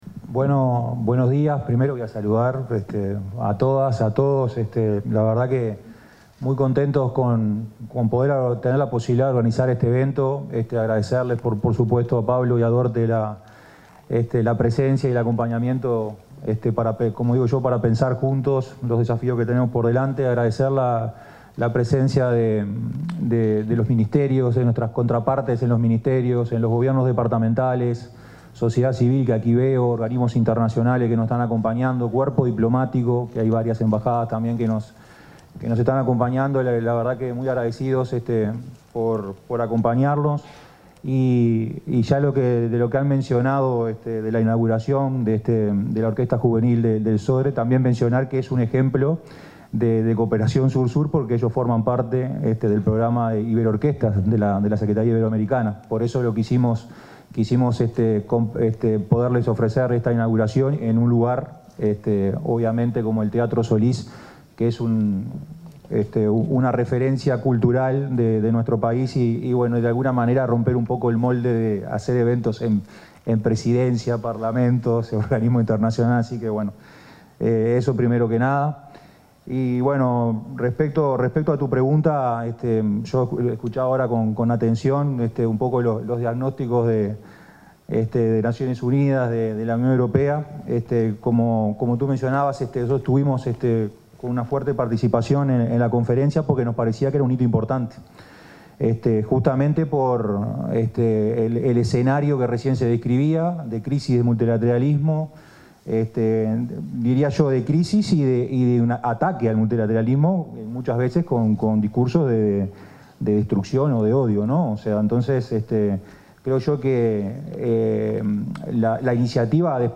Palabras del director ejecutivo de la AUCI, Martín Clavijo 28/08/2025 Compartir Facebook X Copiar enlace WhatsApp LinkedIn El director ejecutivo de la Agencia Uruguaya de Cooperación Internacional (AUCI), Martín Clavijo, se expresó en la apertura del evento Del Compromiso de Sevilla a la Acción.